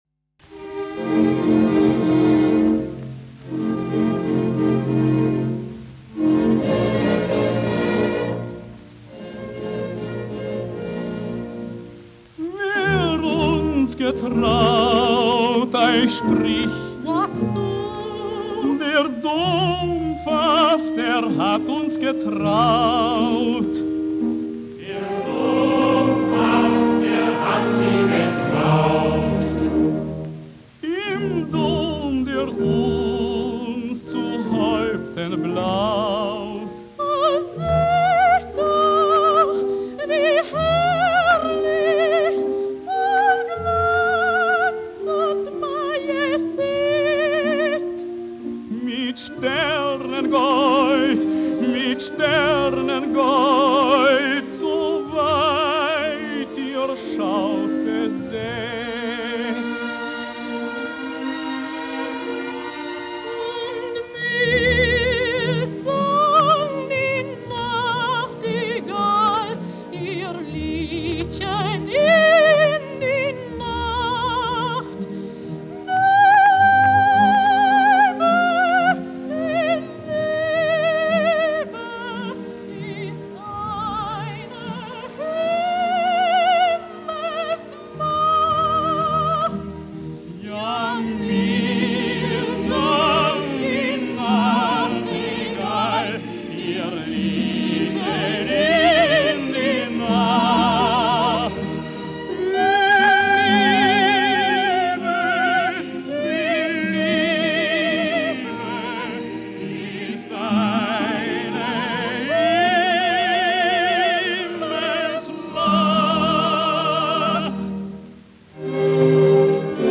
• Schellackplatte